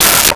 Metal Lid 03
Metal Lid 03.wav